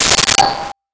Sword Hit 01
Sword Hit 01.wav